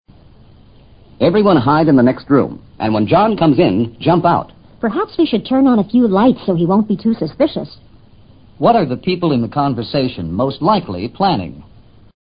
托福听力小对话【2】surprise party